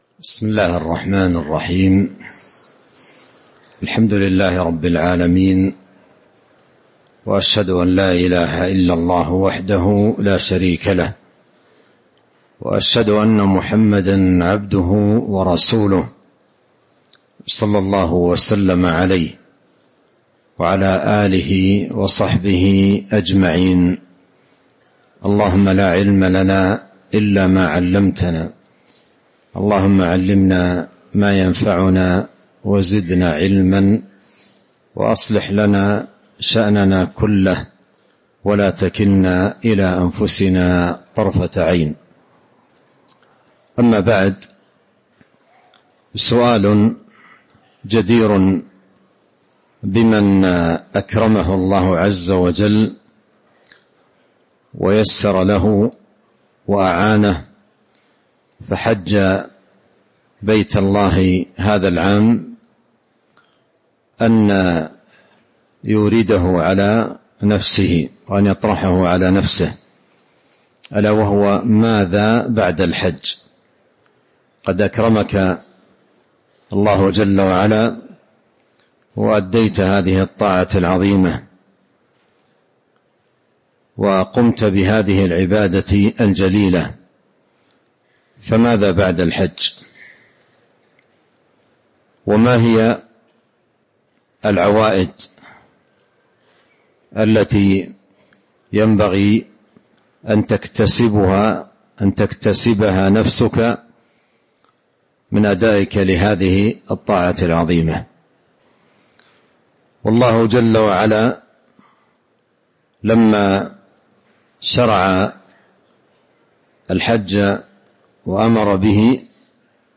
تاريخ النشر ١٧ ذو الحجة ١٤٤٥ هـ المكان: المسجد النبوي الشيخ: فضيلة الشيخ عبد الرزاق بن عبد المحسن البدر فضيلة الشيخ عبد الرزاق بن عبد المحسن البدر ماذا بعد الحج